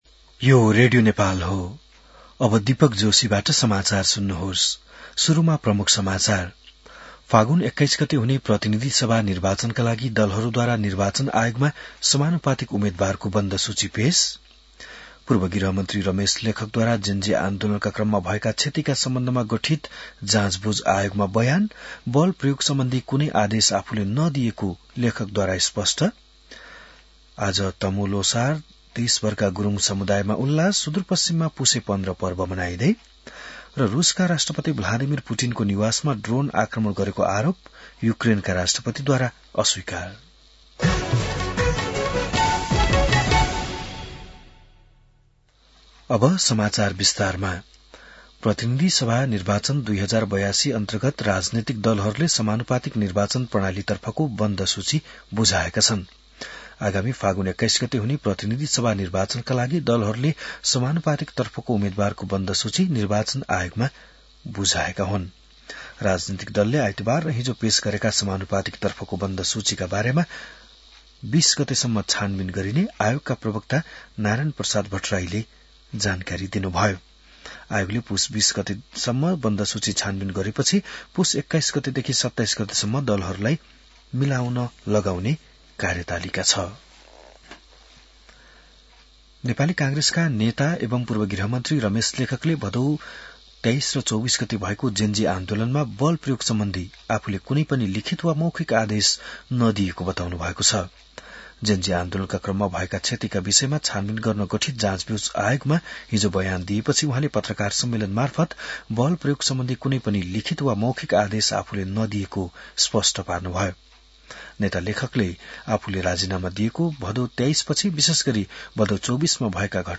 बिहान ९ बजेको नेपाली समाचार : १५ पुष , २०८२